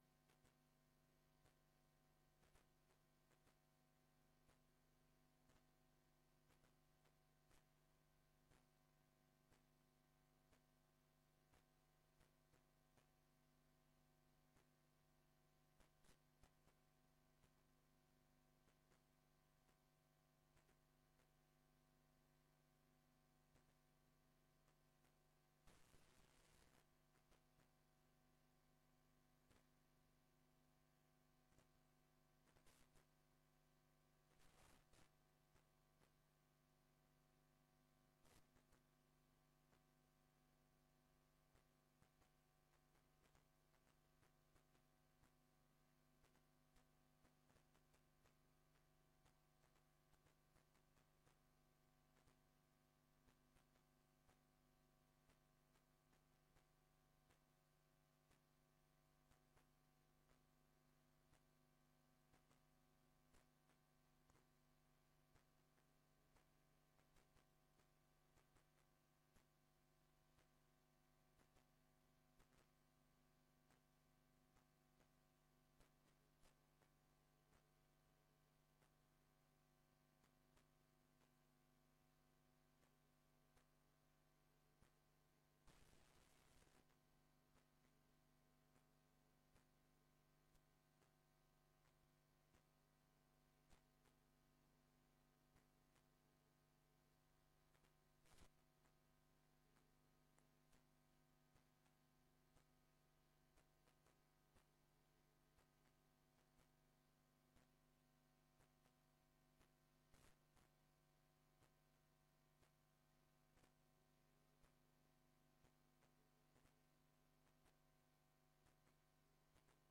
Download de volledige audio van deze vergadering
Locatie: Raadszaal